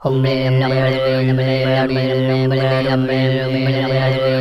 MUMBLERS  -R.wav